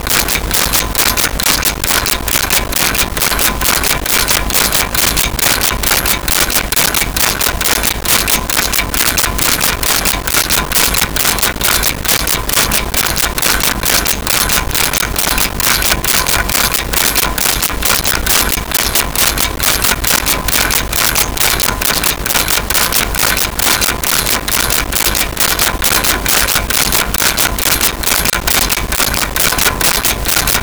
Clock Ticking 5
clock-ticking-5.wav